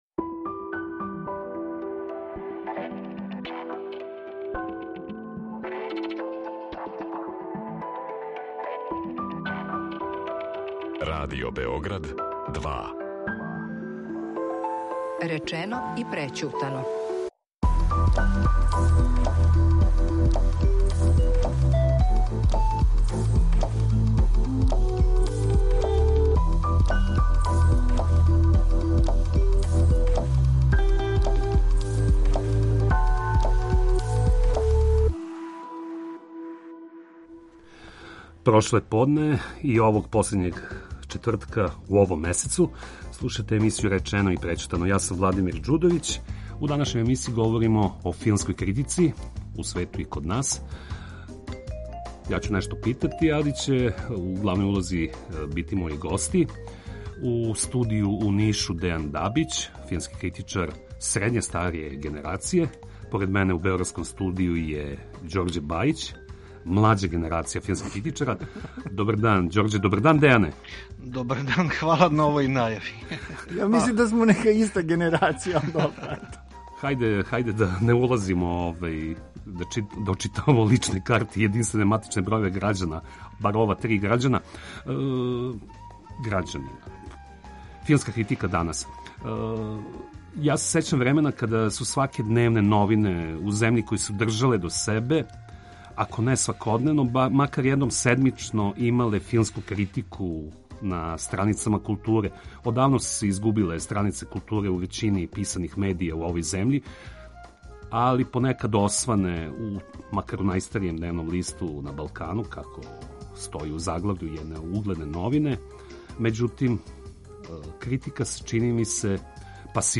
Гости данашње емисије су критичари